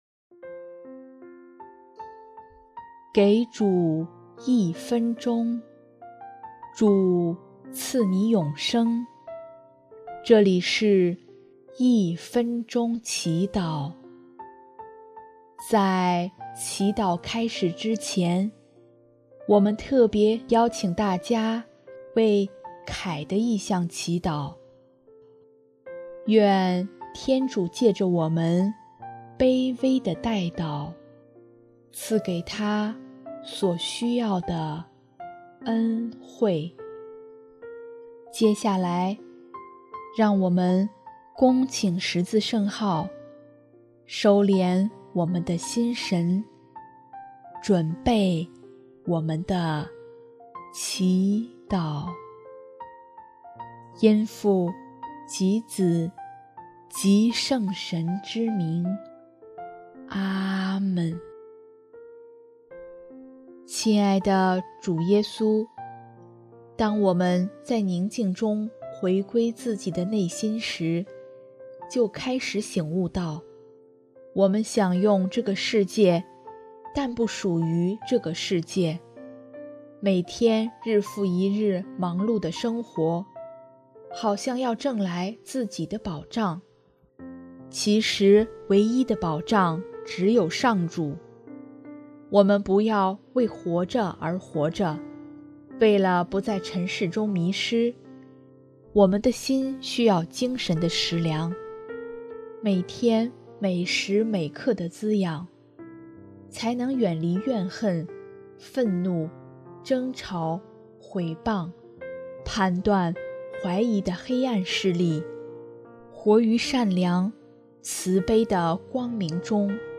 【一分钟祈祷】|8月11日 真正的食粮
音乐： 第三届华语圣歌大赛参赛歌曲优秀奖《有你，不再分离》